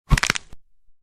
bone_crack.ogg